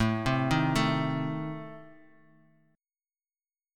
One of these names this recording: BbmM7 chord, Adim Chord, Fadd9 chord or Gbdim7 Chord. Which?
Adim Chord